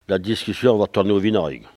parole, oralité
Enquête Arexcpo en Vendée
Catégorie Locution